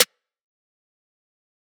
snare3.wav